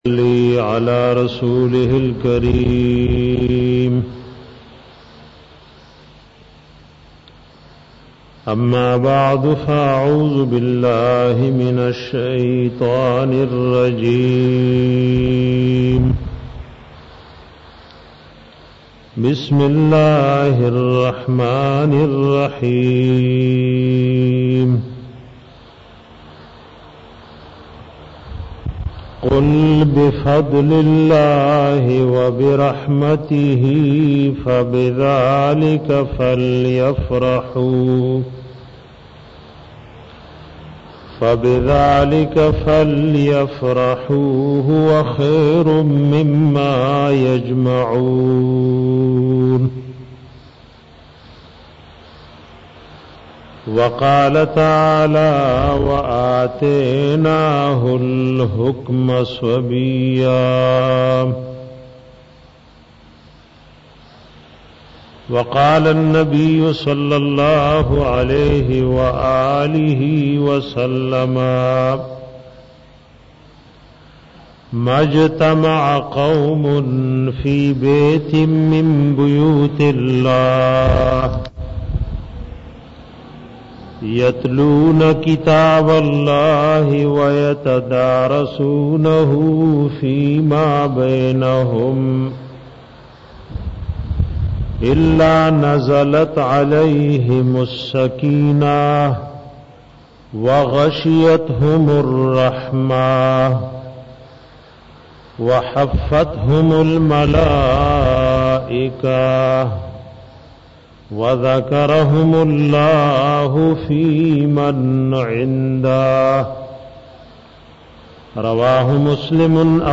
bayan da quran da fazilat